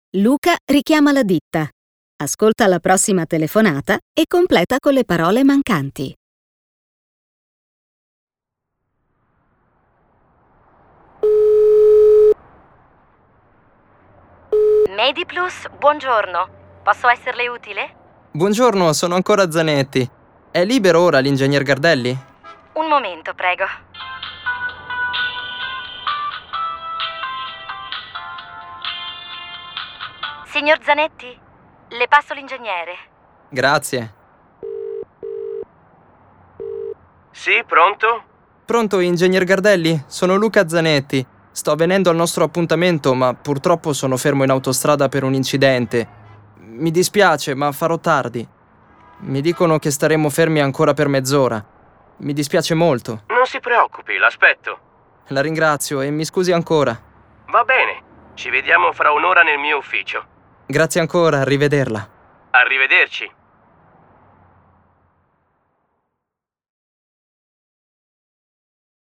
Telefonate (brani audio)